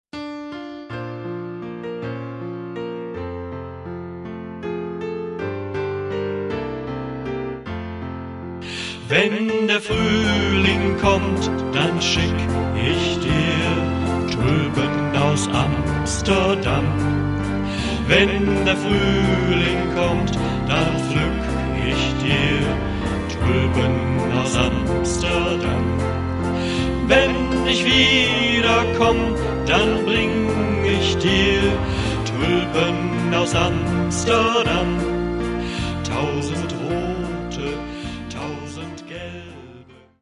Senioren singen mit.